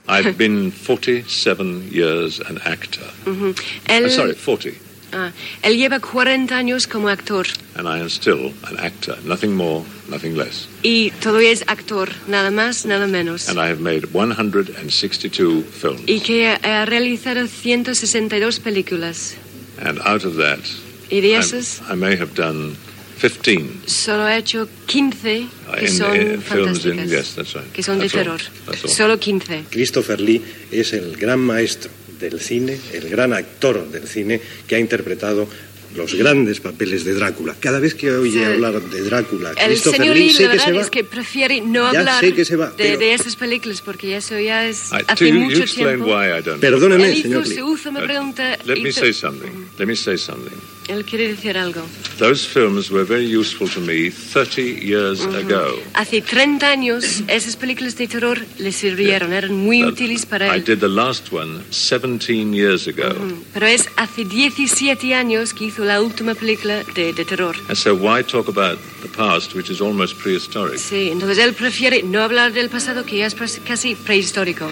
Entrevista a l'actor Christopher Lee (Christopher Frank Carandini Lee), feta al Festival de Cine de Gijón